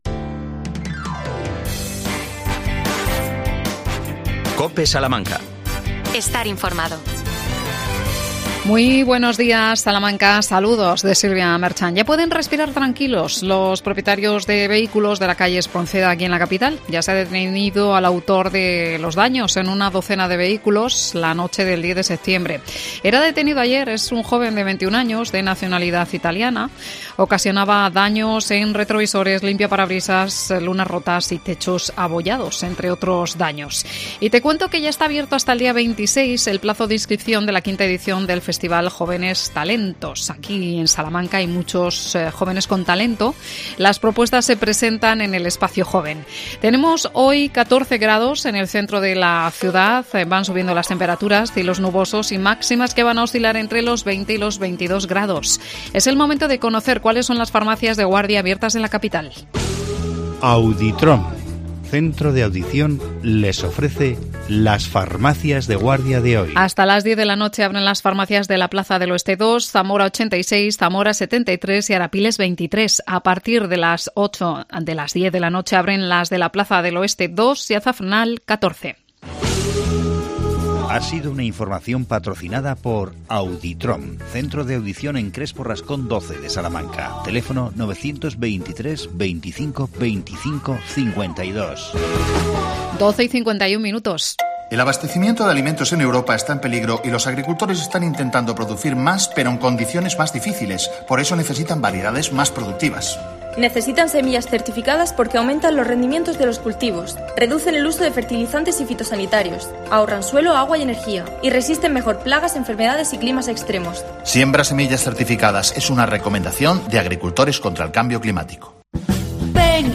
AUDIO: Día 1 de octubre, Día de las Personas Mayores. Entrevistamos al Defensor del Mayor Jesús Sánchez.